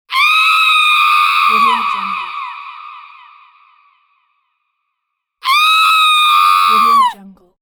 دانلود افکت صوتی جیغ بلند و طولانی یک زن
Woman Screaming royalty free audio track is a great option for any project that requires human sounds and other aspects such as a woman, female and girl.
Sample rate 16-Bit Stereo, 44.1 kHz
Looped No